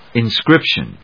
音節in・scrip・tion 発音記号・読み方
/ɪnskrípʃən(米国英語)/